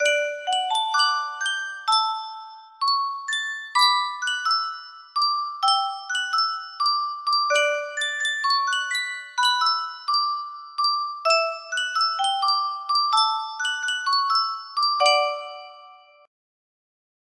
Yunsheng Music Box - Brahms Clarinet Quintet 6001 music box melody
Full range 60